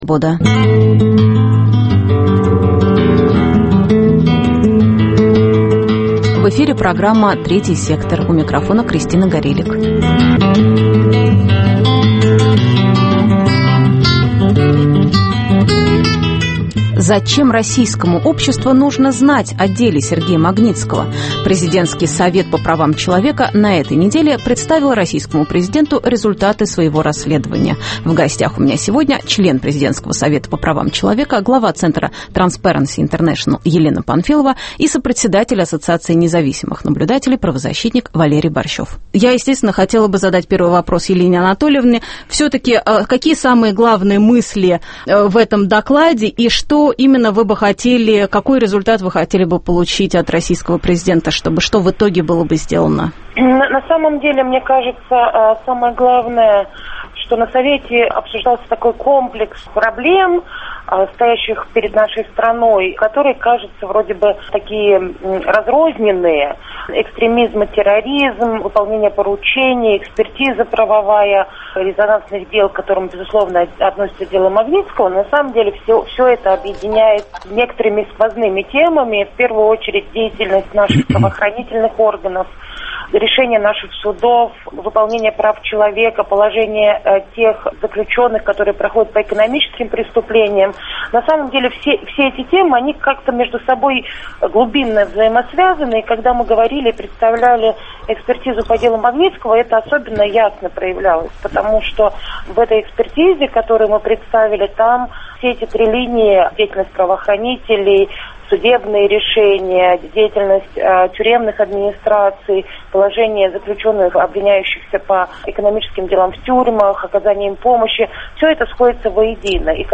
Зачем российскому обществу нужно знать о деле Магнитского? В гостях сопредседатель Ассоциации независимых наблюдателей, правозащитник Валерий Борщев и глава центра Transparency International, член Президентского совета по развитию гражданского общества и правам человека Елена Панфилова. Рубрика Людмилы Алексеевой: об общественных наблюдателях за тюрьмами.